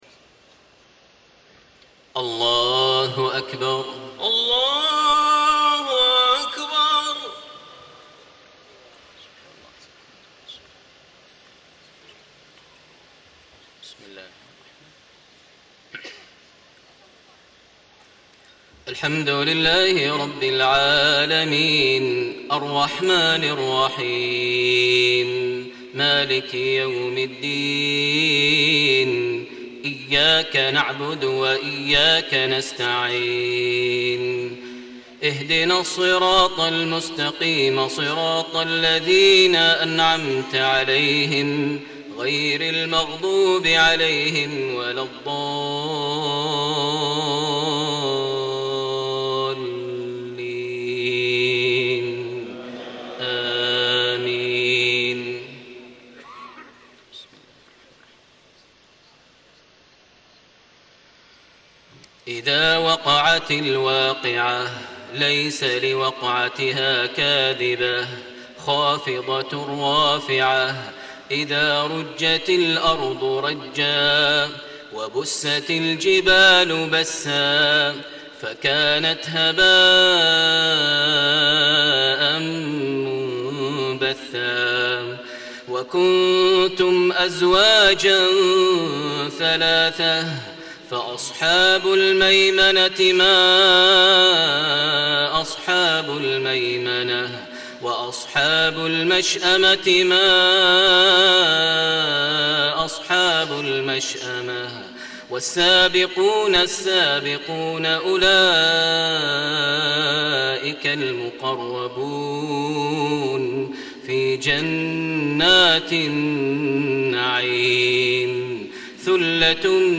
صلاة العشاء 7-7-1434هـ من سورة الواقعة > 1434 🕋 > الفروض - تلاوات الحرمين